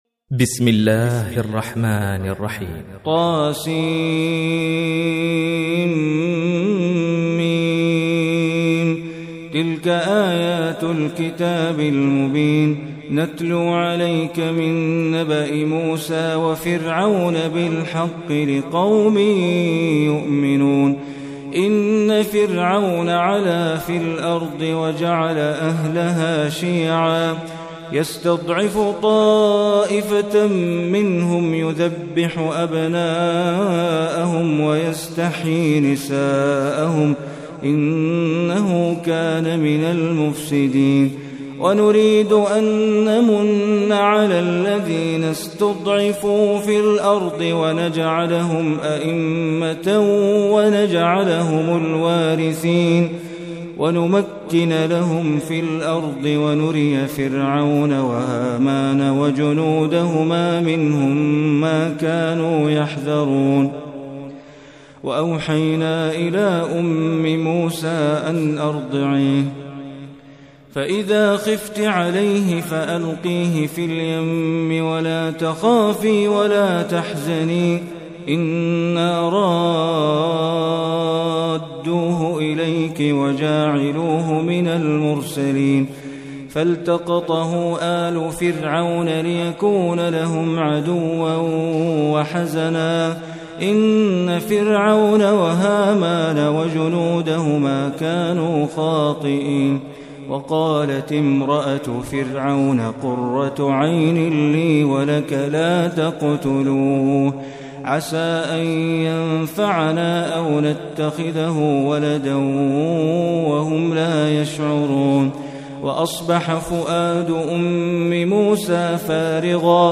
Surah Al-Qasas MP3 Recitation by Bandar Baleela
Surah Al-Qasas is 28 Surah of Holy Quran. Listen beautiful surah recitation in the voice of Sheikh Bandar Baleela.